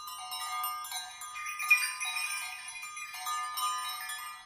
Dans cette page nous offrons des sonneries issues d’enregistrements de troupeaux.
Crète : mobile de sonnailles